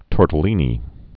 (tôrtl-ēnē)